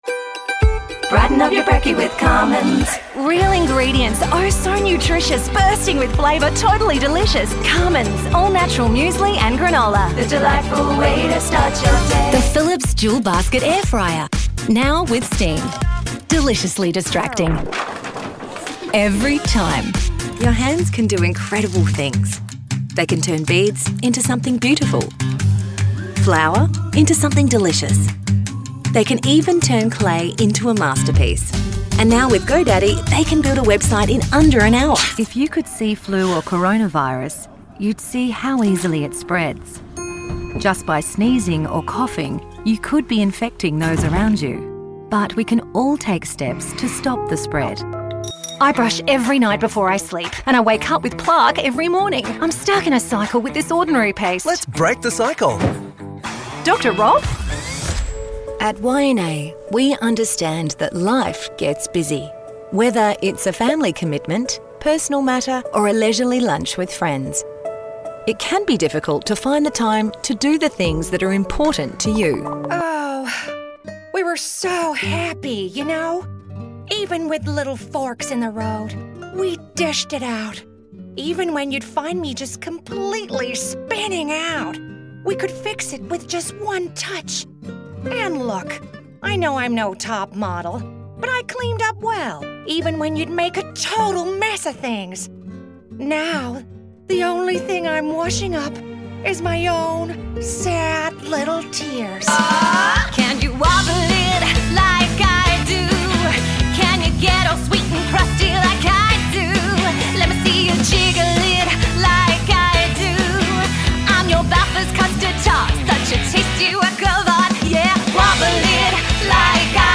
Englisch (Australisch)
Schwül
Sexy
Glatt